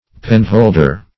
Penholder \Pen"hold`er\, n. A handle for a pen.